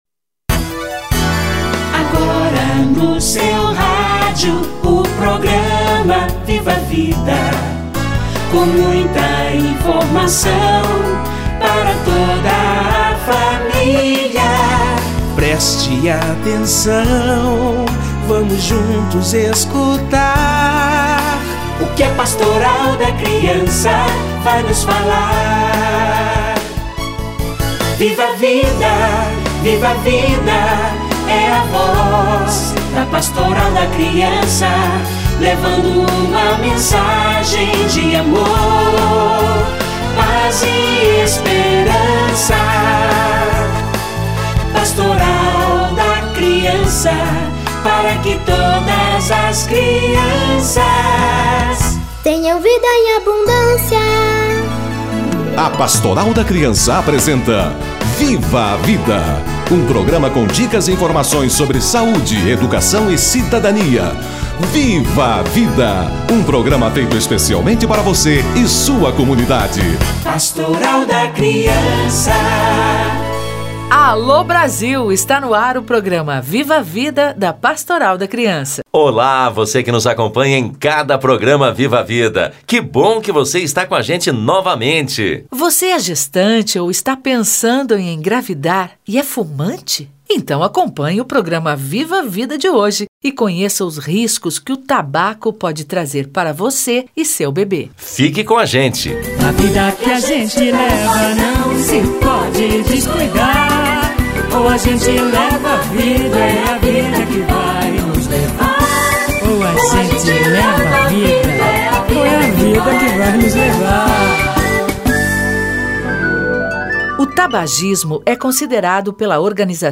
Os males do tabaco - Entrevista